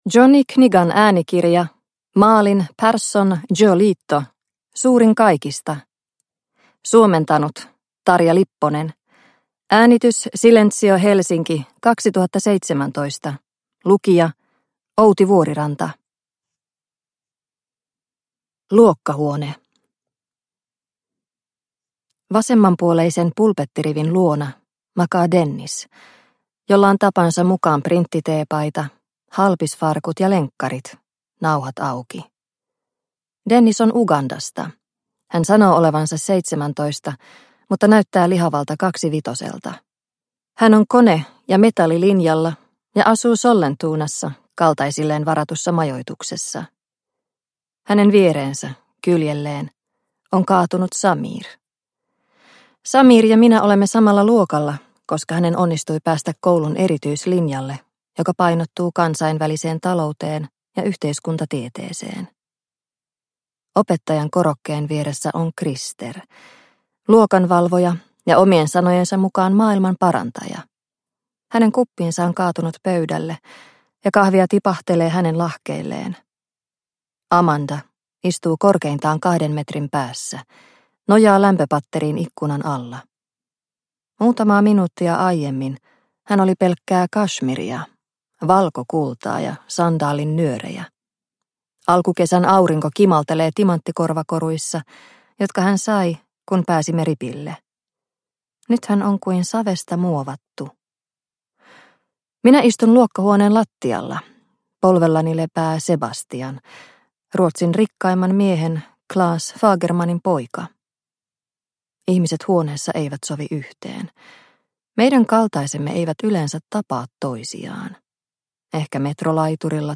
Suurin kaikista – Ljudbok – Laddas ner